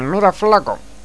(popular canario)